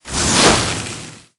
戈仑冰人Ice Golem是一张适合拉扯的卡，是个哑巴，不会说话。
攻击音效
CR_ice_golem_atk_01.mp3